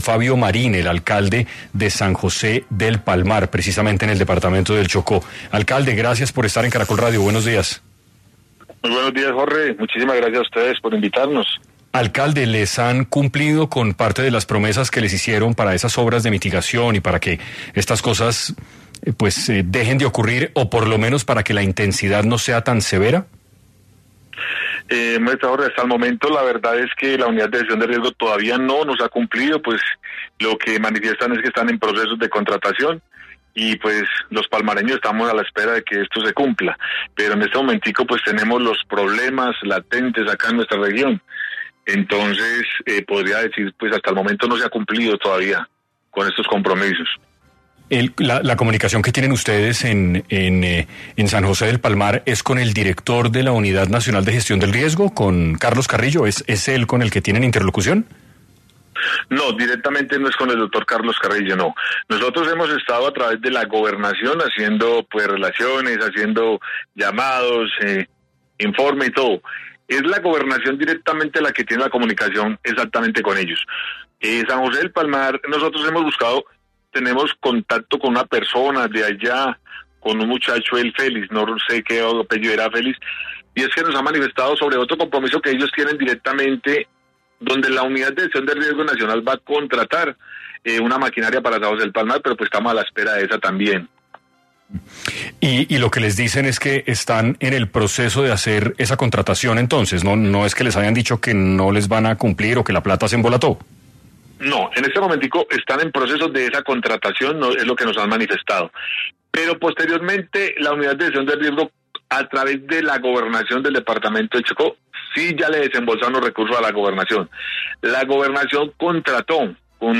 En entrevista para 6AM, León Fabio Marín, alcalde de San José del Palmar, Chocó, expresó que el Gobierno Nacional ha incumplido con las obras prometidas el invierno pasado.